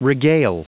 Prononciation du mot regale en anglais (fichier audio)
Prononciation du mot : regale